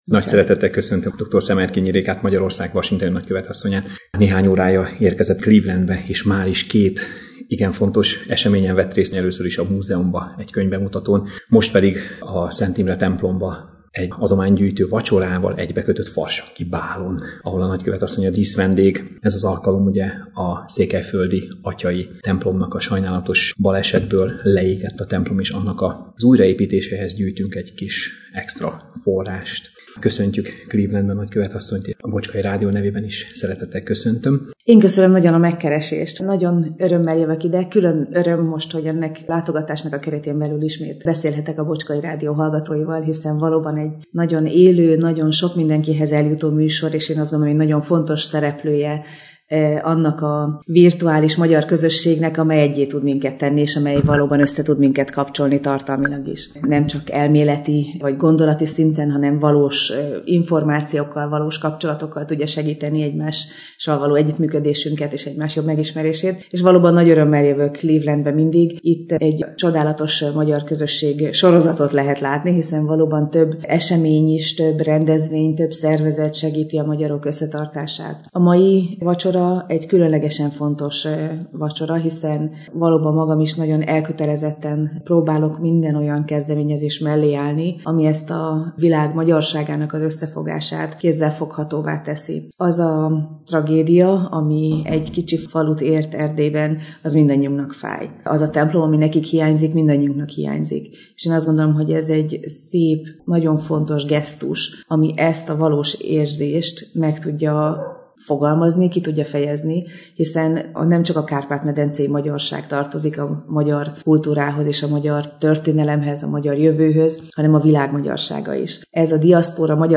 A clevelandi látogatás alatt úgy gondoltuk, hogy egy rövid interjú erejéig beszélgetésre invitáljuk a nagykövet asszonyt.